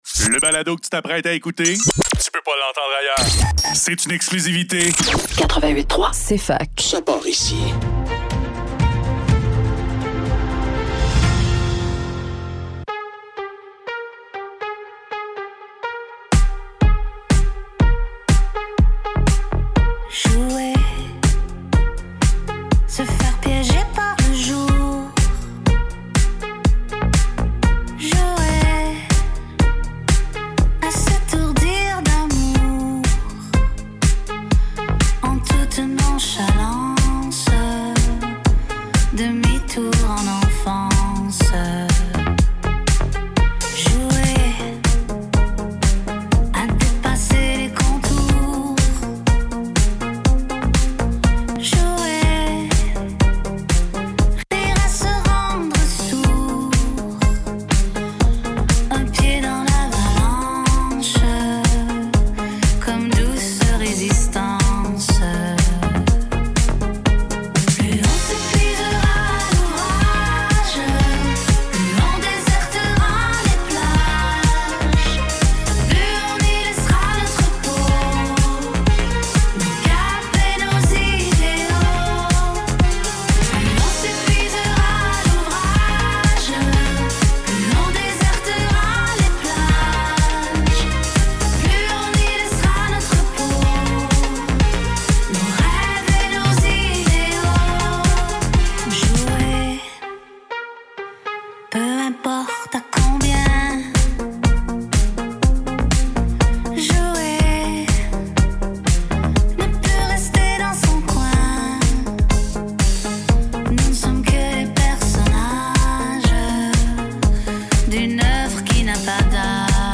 Le Neuf - Entrevue Ariane Moffatt - 26 Mars 2025